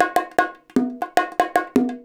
100 BONGO5.wav